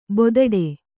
♪ bodaḍe